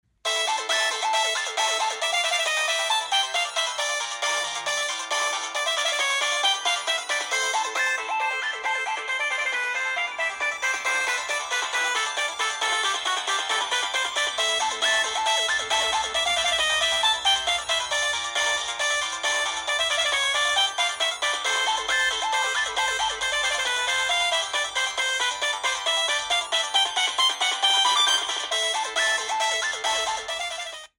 Kategori: Nada dering
monophonic...